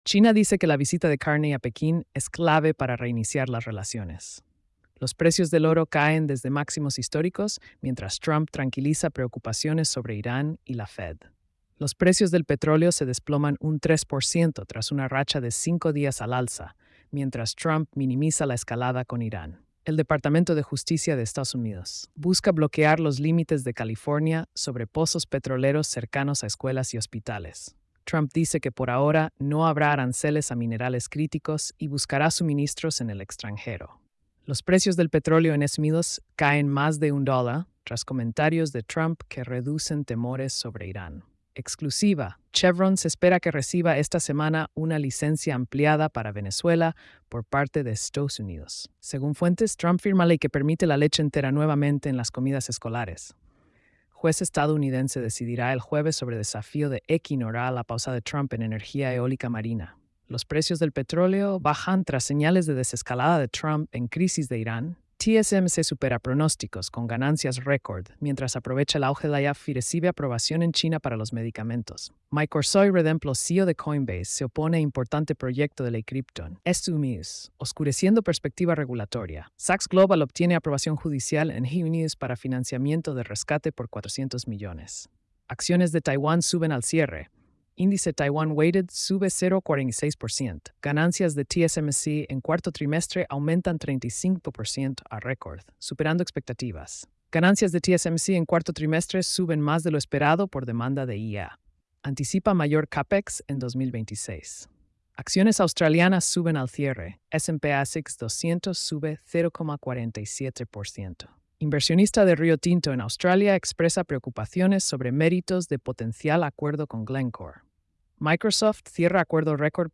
🎧 Resumen Económico y Financiero.